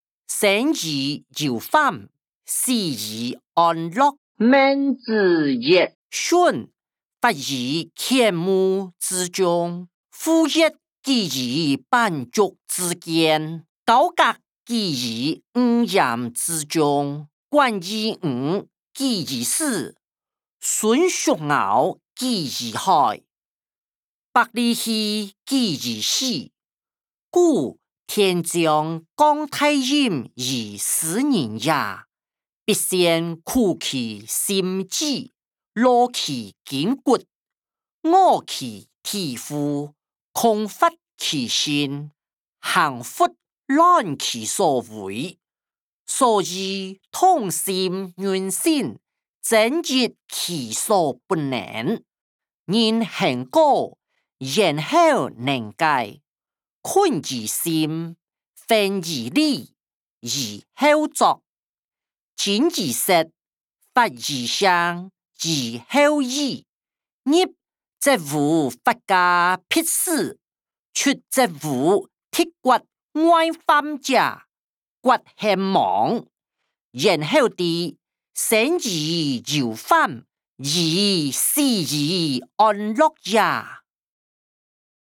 經學、論孟-生於憂患，死於安樂音檔(大埔腔)